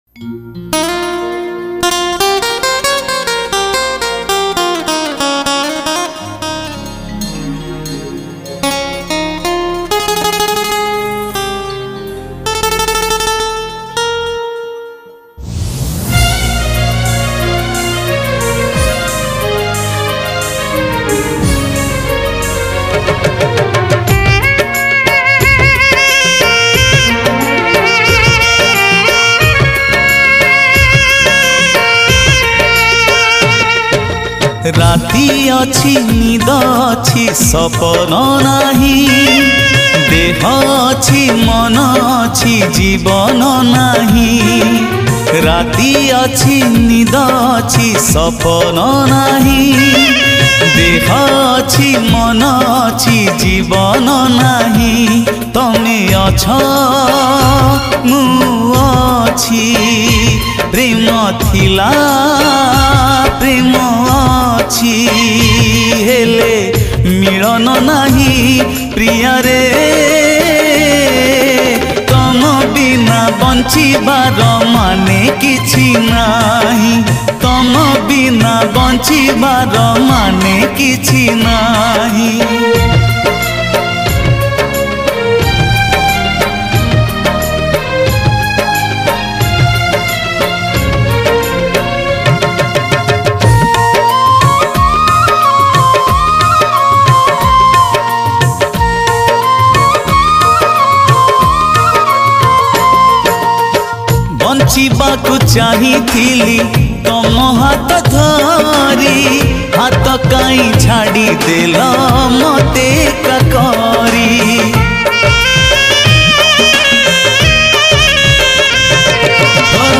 Odia Modern Album Songs